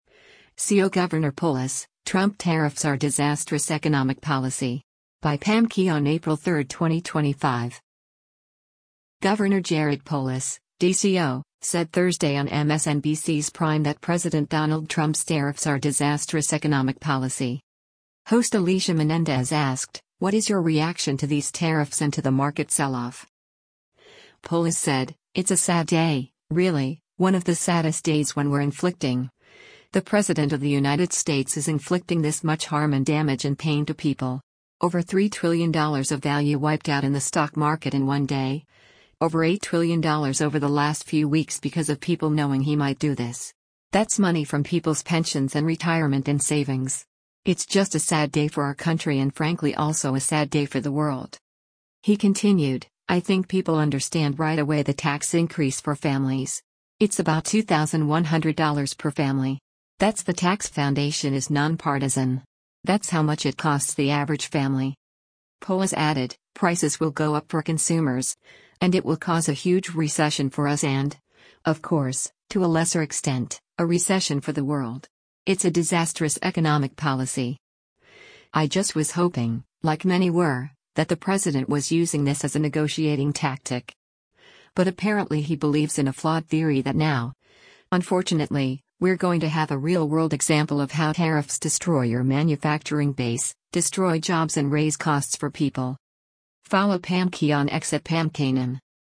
Governor Jared Polis (D-CO) said Thursday on MSNBC’s “Prime” that President Donald Trump’s tariffs are “disastrous economic policy.”
Host Alicia Menendez asked, ” What is your reaction to these tariffs and to the market sell-off?”